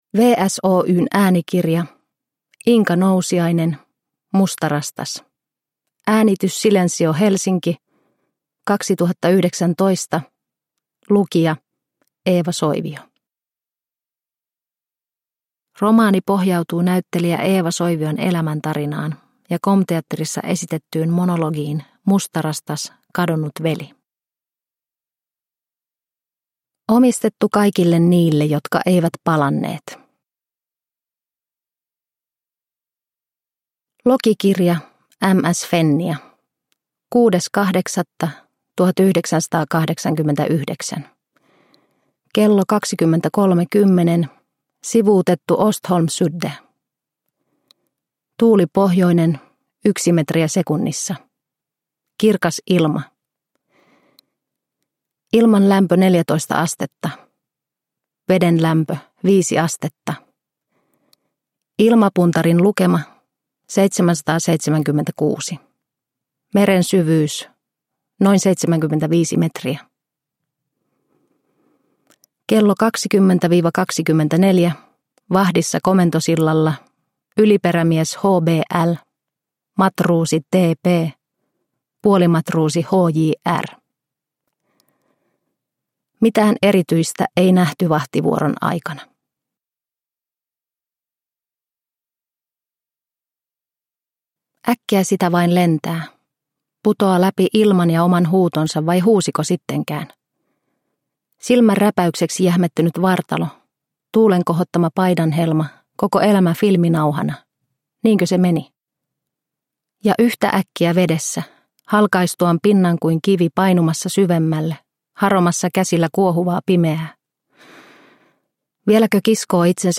Mustarastas – Ljudbok – Laddas ner